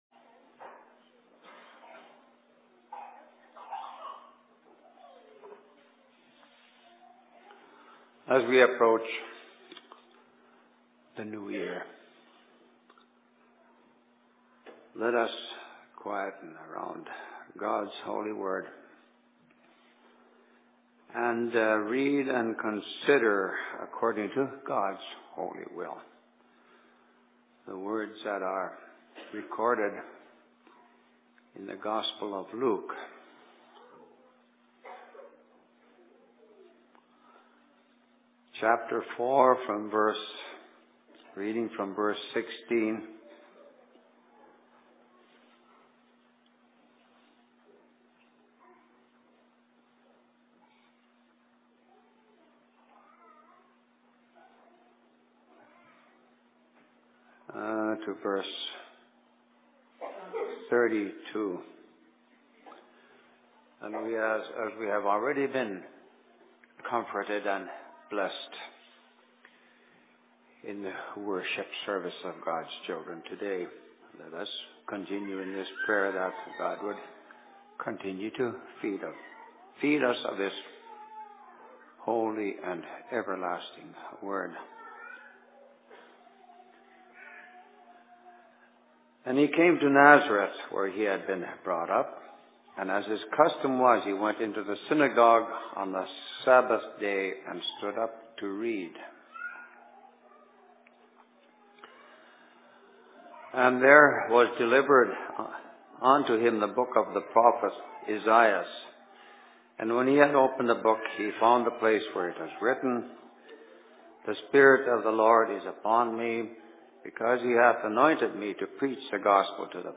Sermon in Outlook 31.12.2006
Location: LLC Outlook